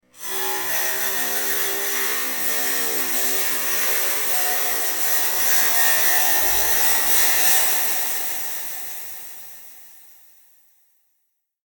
Sci-fi-metallic-spring-scraping-sound-effect.mp3